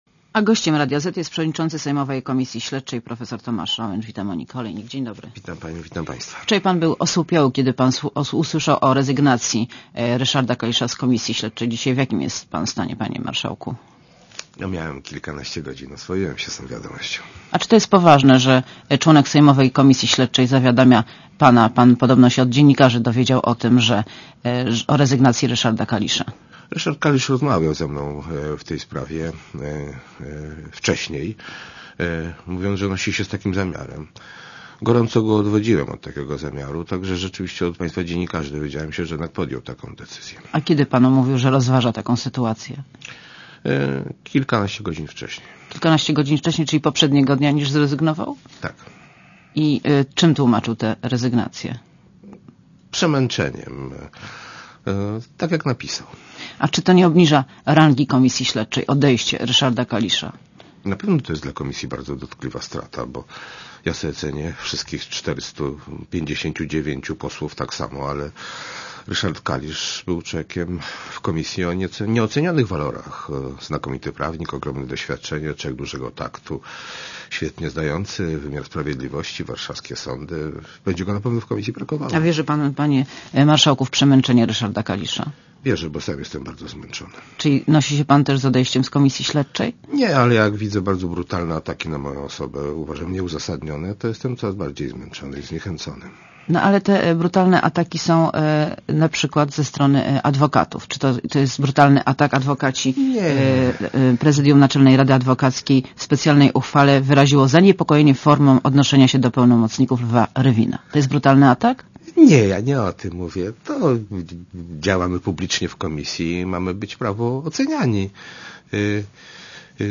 Monika Olejnik rozmawia z Tomaszem Nałęczem - przewodniczącym sejmowej komisji śledczej, i Zbigniewem Ziobro - członkiem tej komisji